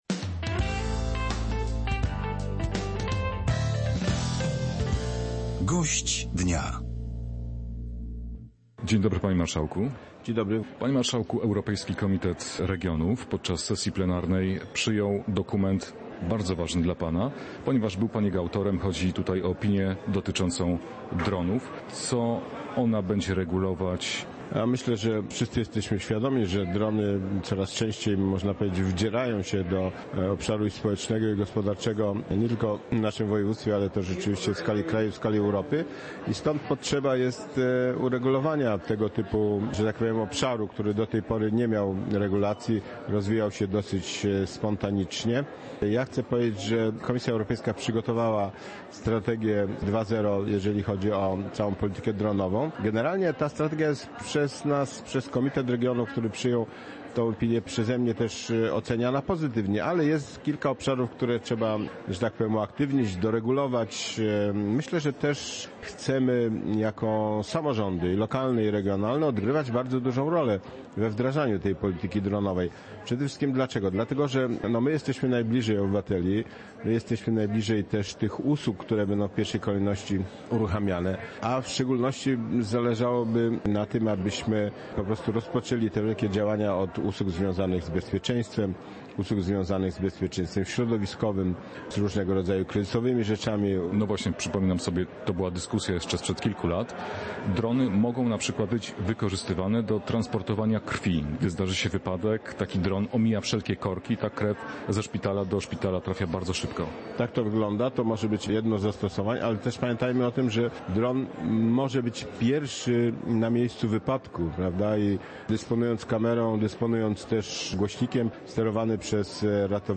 Gość Polskiego Radia Rzeszów zwrócił uwagę na rosnącą rangę dronów w codziennym życiu.